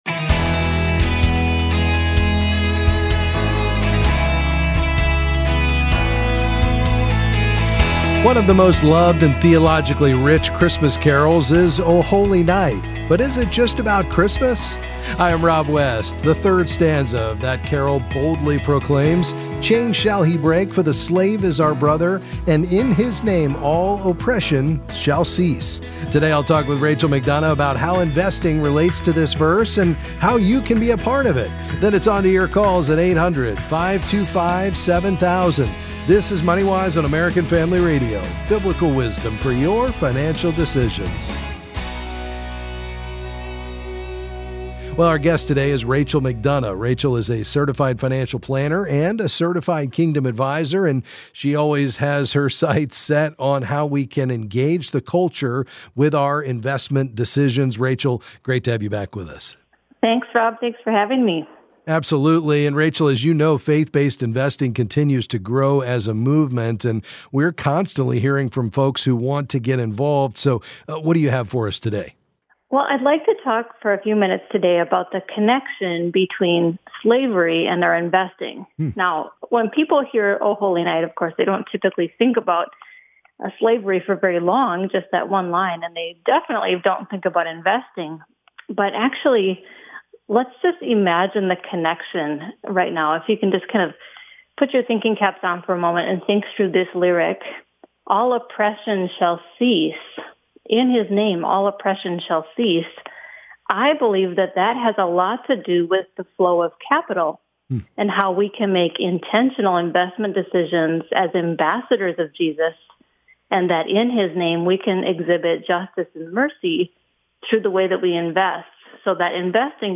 Then we'll open our phone lines for your questions on any financial topic.